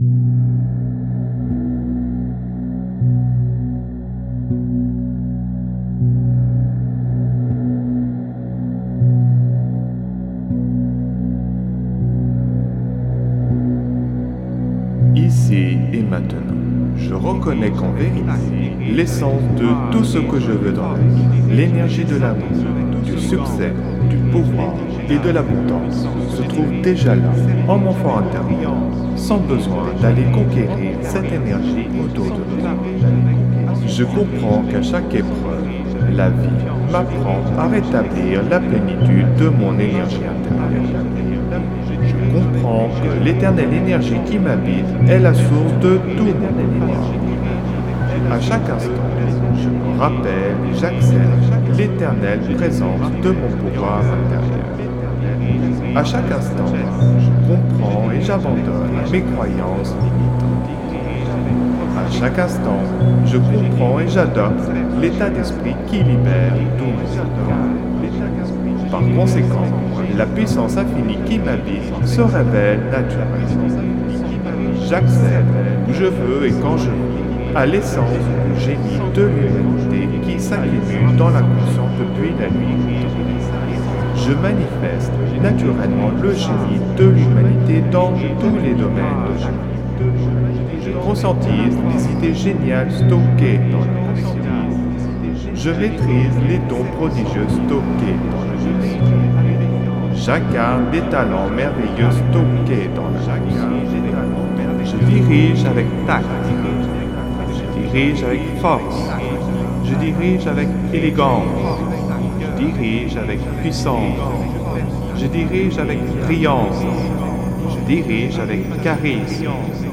(Version ÉCHO-GUIDÉE)
Alliage ingénieux de sons et fréquences curatives, très bénéfiques pour le cerveau.
Ondes gamma musicales de qualité de supérieure 61,53 Hz (SI 123,47 Hz et FA# 185,00 Hz).
Sons isochrones très bénéfiques pour le cerveau.
Fréquences Solfeggio hautement curatives.
Effet 3D subliminal écho-guidée.